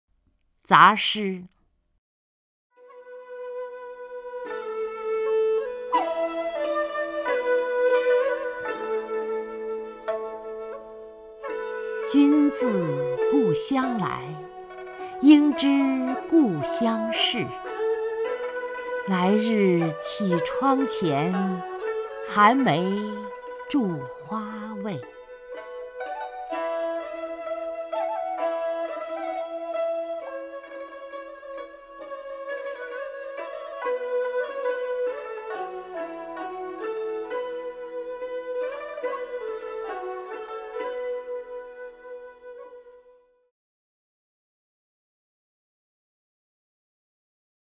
曹雷朗诵：《杂诗》(（唐）王维) （唐）王维 名家朗诵欣赏曹雷 语文PLUS